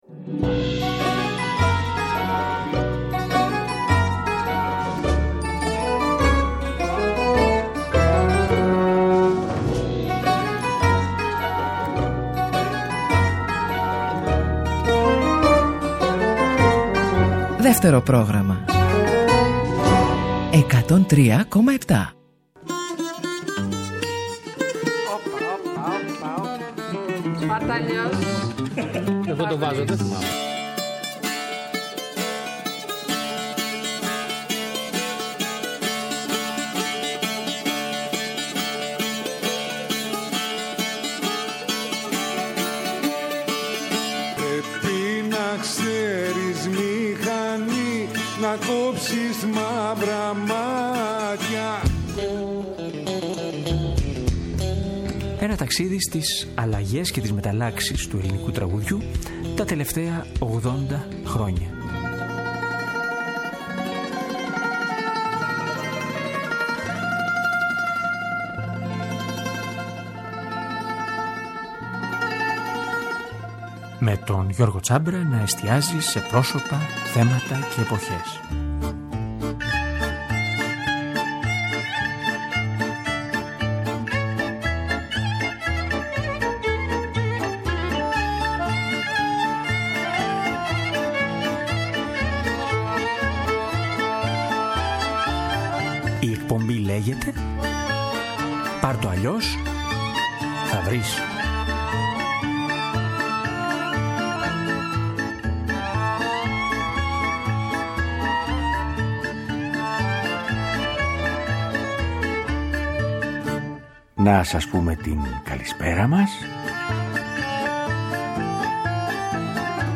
Είπαμε λοιπόν σήμερα στην εκπομπή να ακούσουμε τραγούδια που έχει γράψει στα 60 χρόνια της διαδρομής του. Είναι αρκετά αυτά που άντεξαν στα χρόνια .